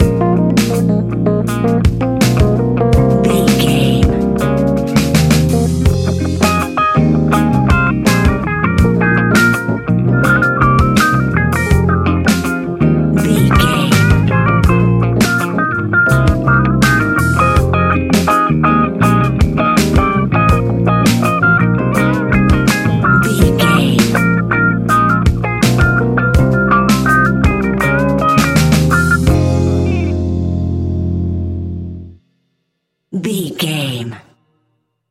Epic / Action
Fast paced
In-crescendo
Uplifting
Ionian/Major
F♯
hip hop